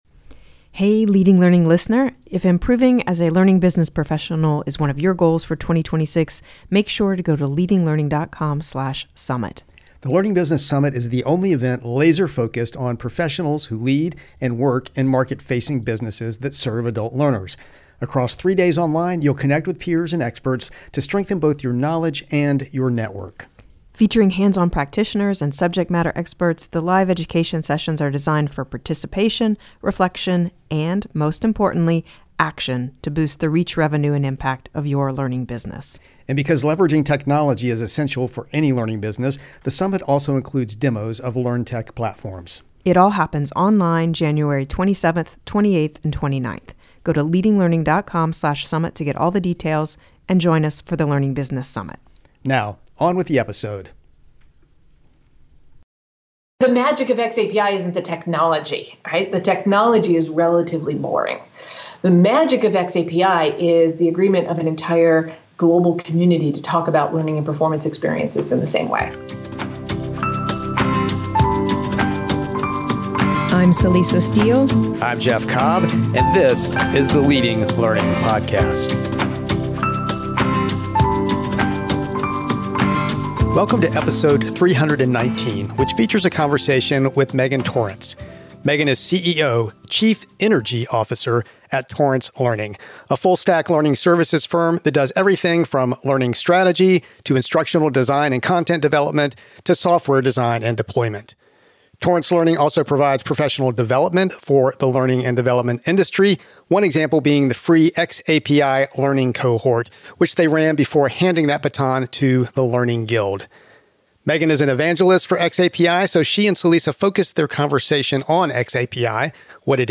In this episode of the Leading Learning Podcast, co-host